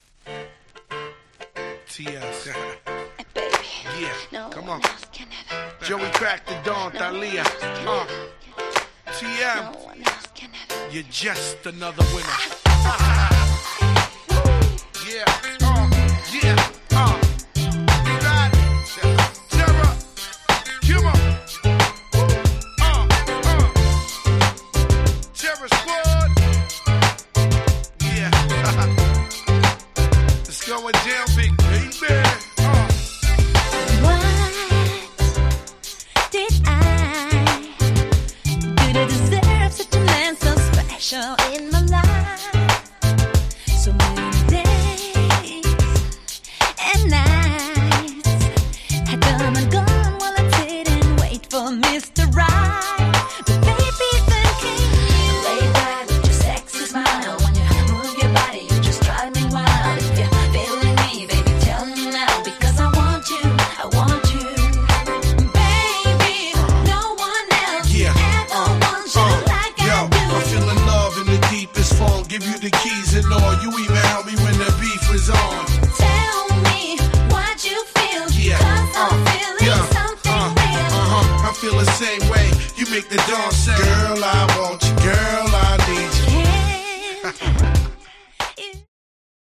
問答無用の00's R&B Classic !!
程良くキャッチーで使い易くて最高です！
キャッチー系 00's